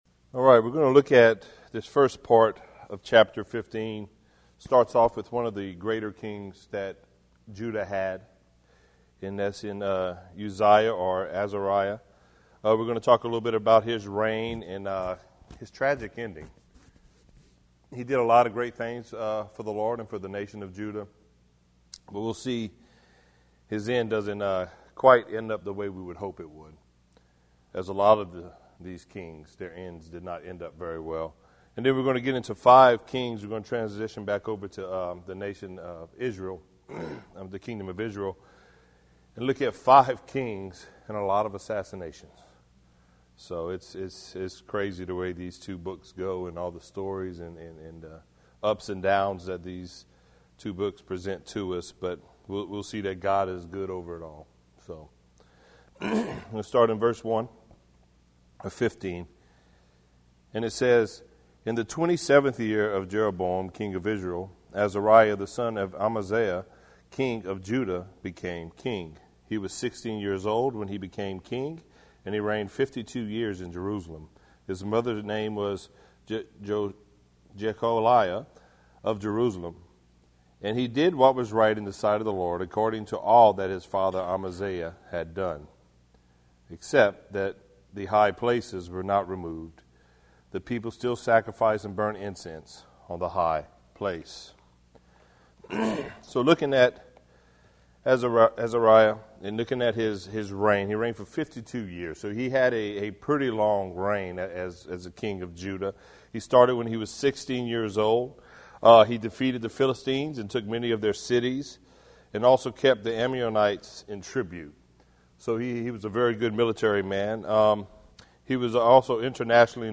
verse by verse study